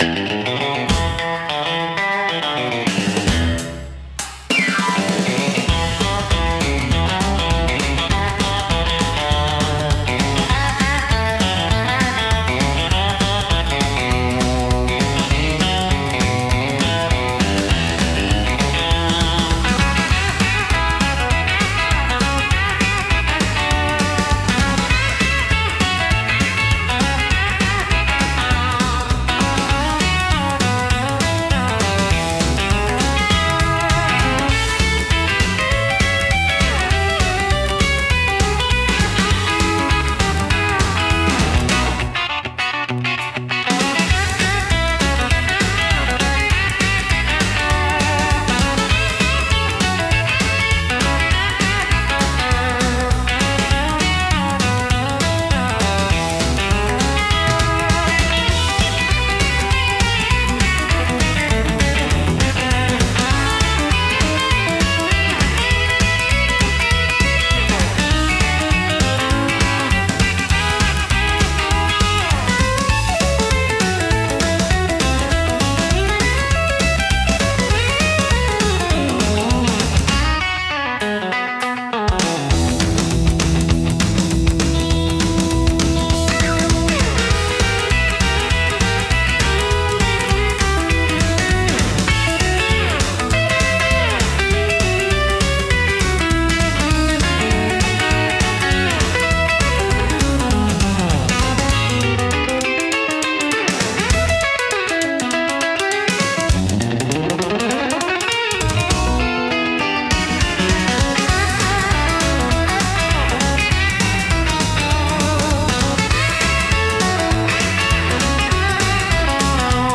懐かしさと若々しいエネルギーが共存し、楽しい空気づくりに効果的なジャンルです。